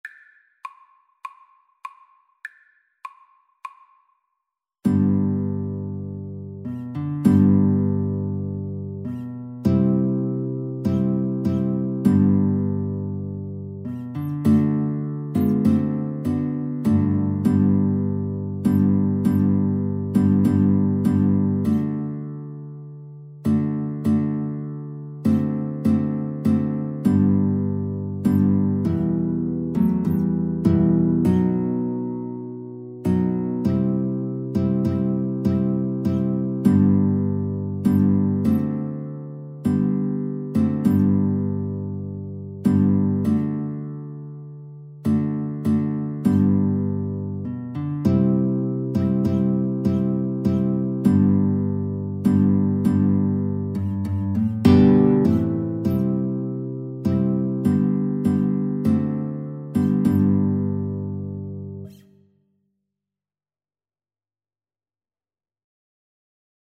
4/4 (View more 4/4 Music)
Guitar-Flute Duet  (View more Easy Guitar-Flute Duet Music)
Classical (View more Classical Guitar-Flute Duet Music)